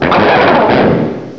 sovereignx/sound/direct_sound_samples/cries/gurdurr.aif at master